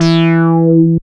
70.02 BASS.wav